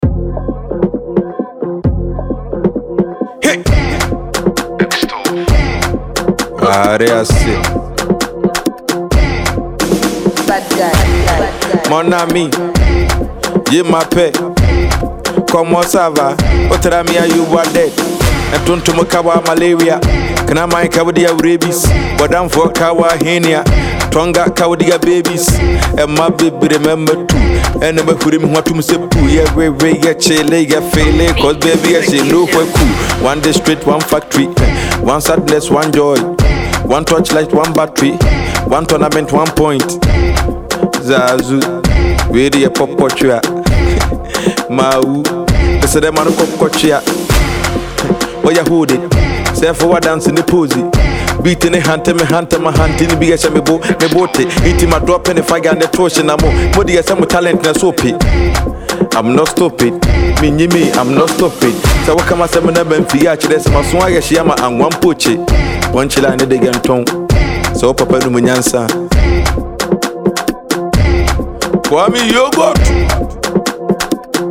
Prolific Ghanaian rapper